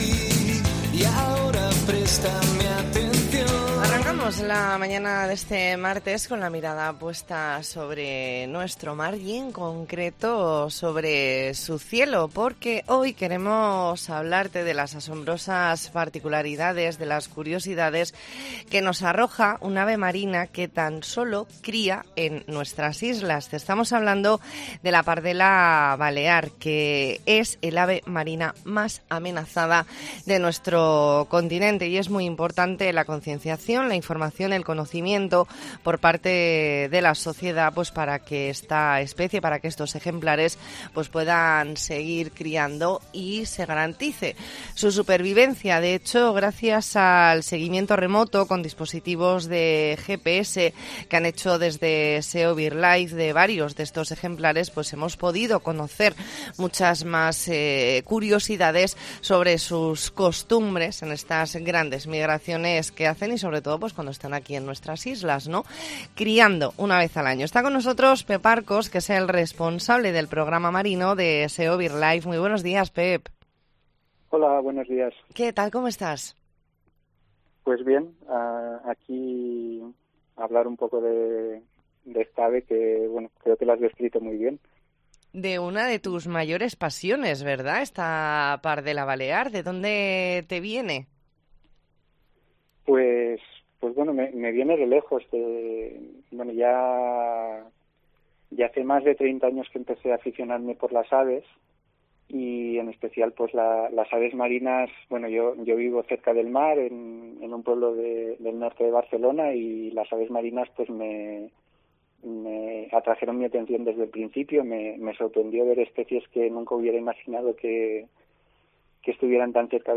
E ntrevista en La Mañana en COPE Más Mallorca, martes 18 de octubre de 2022.